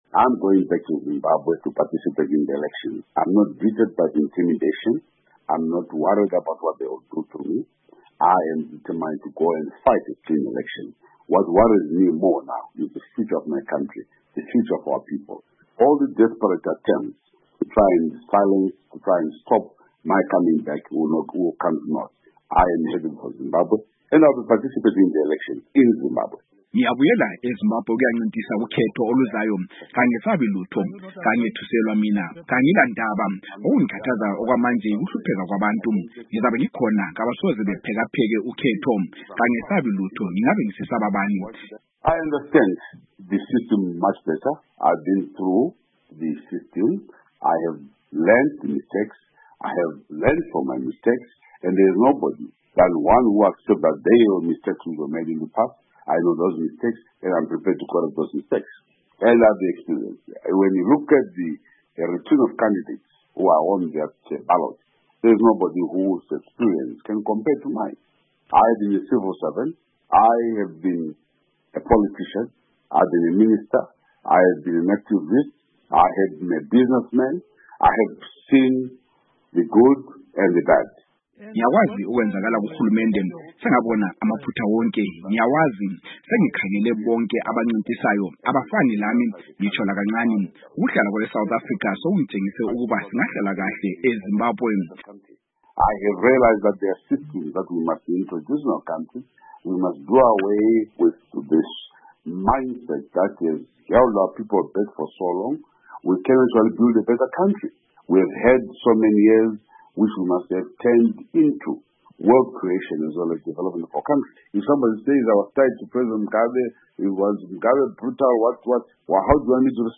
Ingxoxo Esiyenze LoMnu. Saviour Kasukuwere